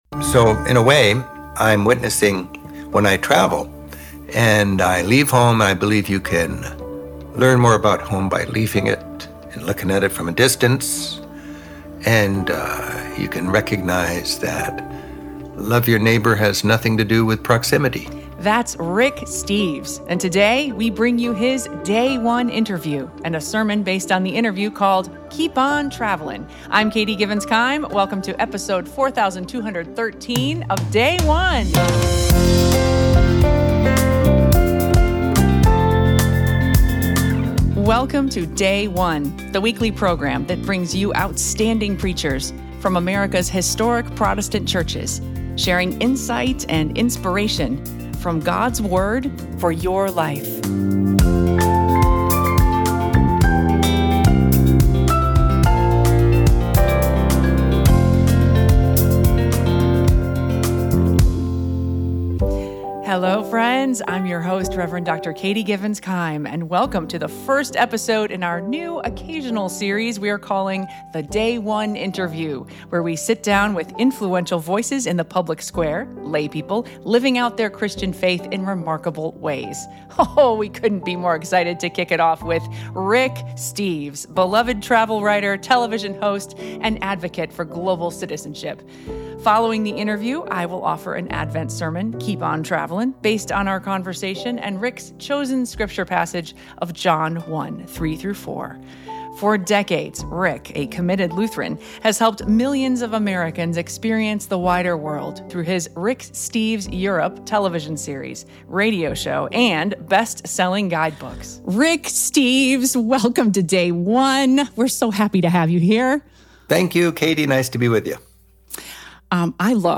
Each episode features a thought-provoking message from a different preacher, accompanied by interviews that explore the sermon text and discuss how you can apply these teachings to your everyday life.